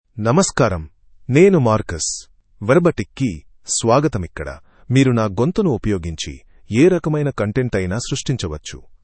Marcus — Male Telugu AI voice
Marcus is a male AI voice for Telugu (India).
Voice sample
Male
Marcus delivers clear pronunciation with authentic India Telugu intonation, making your content sound professionally produced.